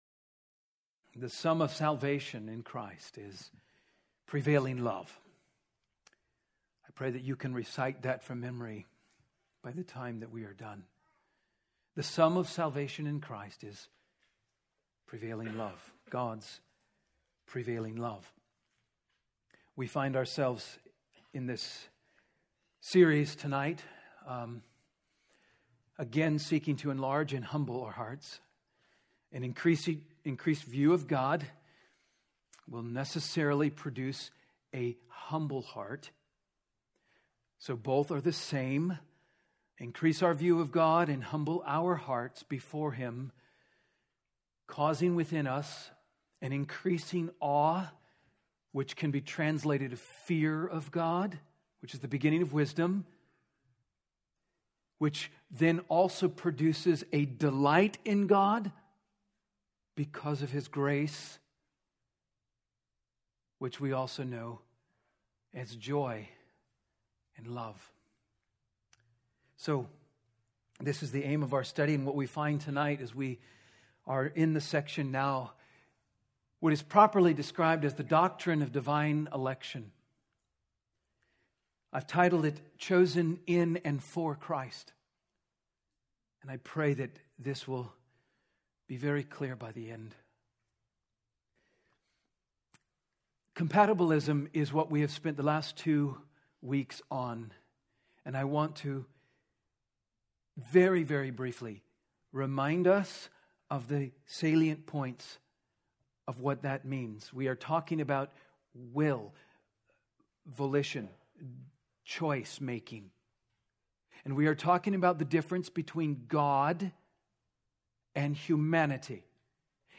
Wednesday Sermons - Trinity Bible Church